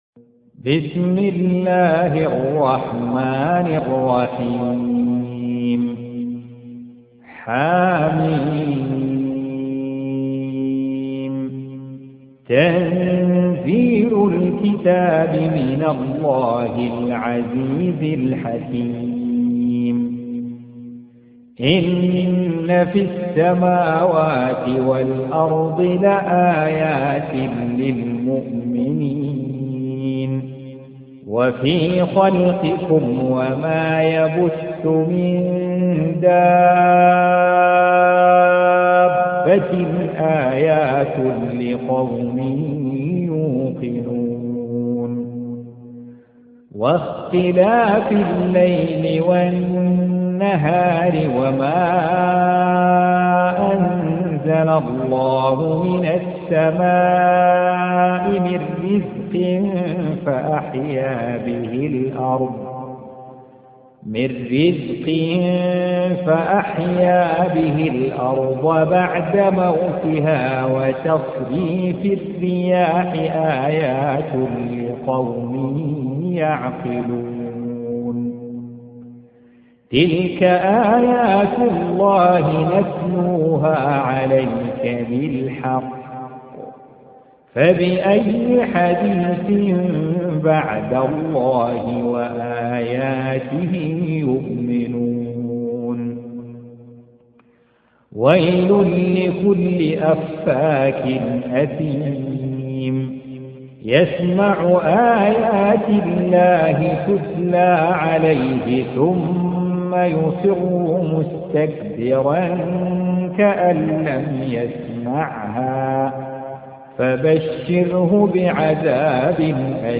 Surah Repeating تكرار السورة Download Surah حمّل السورة Reciting Murattalah Audio for 45. Surah Al-J�thiya سورة الجاثية N.B *Surah Includes Al-Basmalah Reciters Sequents تتابع التلاوات Reciters Repeats تكرار التلاوات